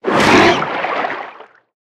Sfx_creature_snowstalkerbaby_flinch_swim_01.ogg